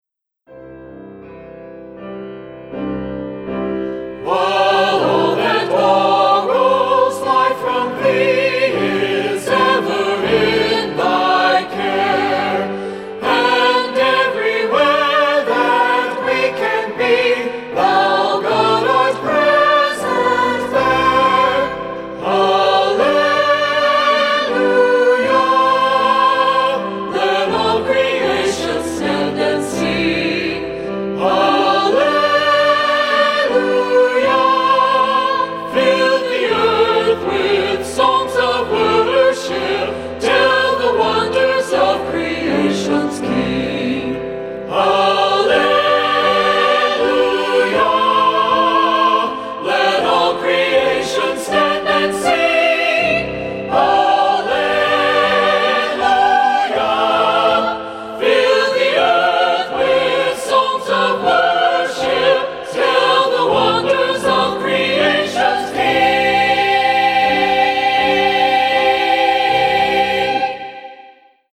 STUDIO: Broadway Recording Studio, Broken Bow, OK
CONGREGATIONAL ANTHEM DEMOS